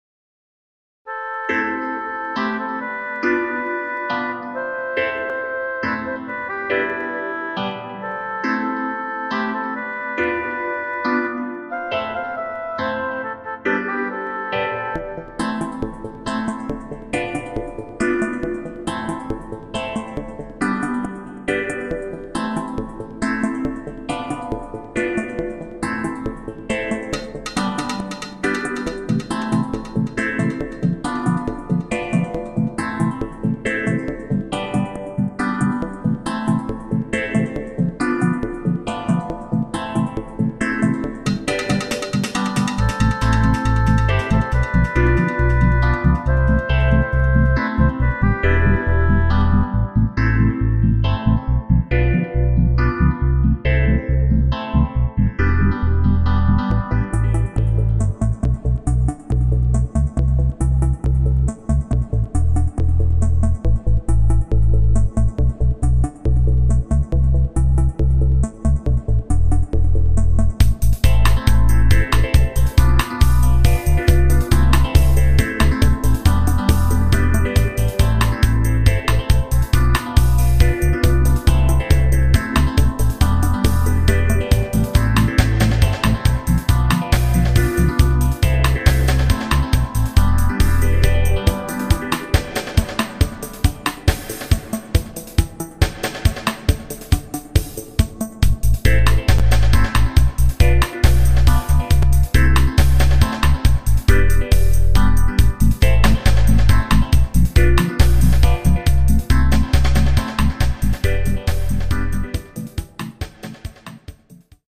Home made dub part2.... with reason & unfortunalty just reason!